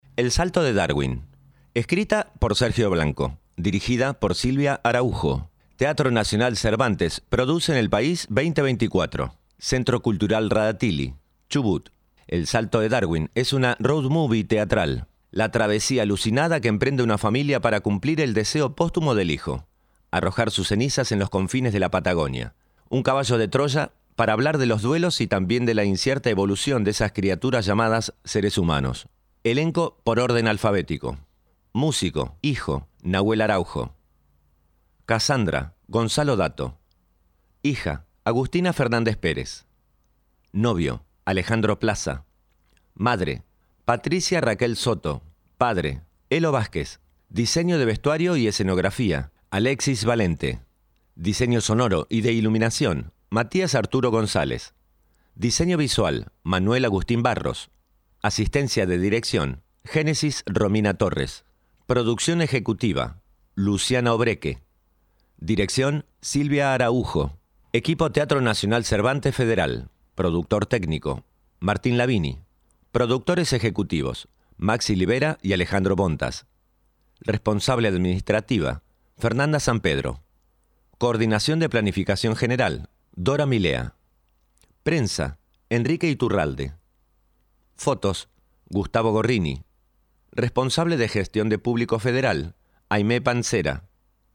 El siguiente audio corresponde a lectura del programa de mano del espectáculo El salto de Darwin